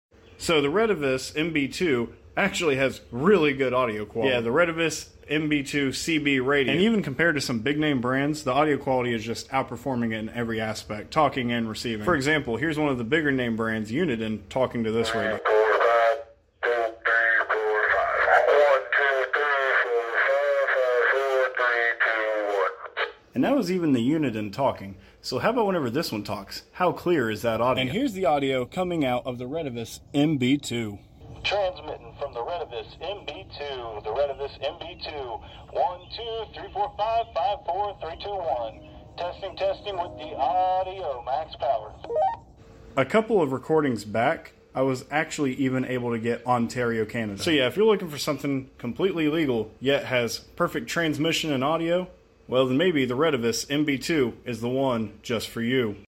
Retevis MB2 Audio Quality